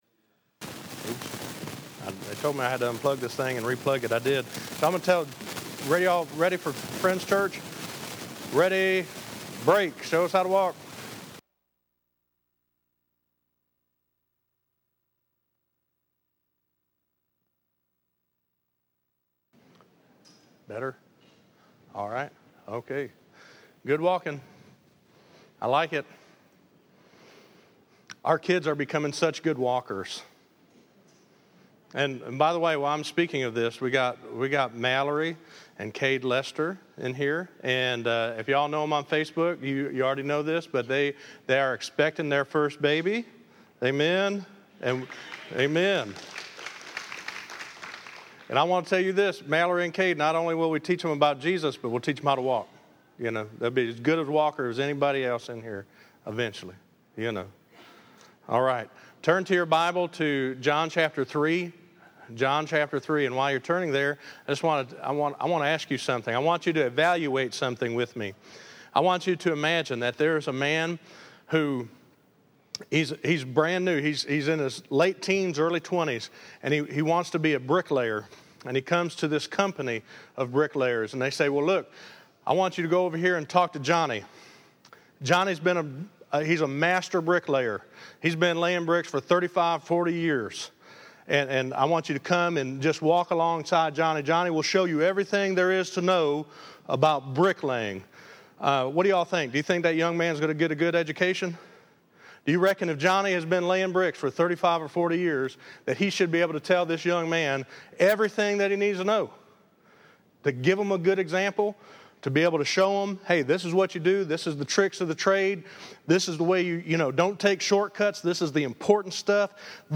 Listen to HE MUST INCREASE but I must decrease - 03_15_15_Sermon.mp3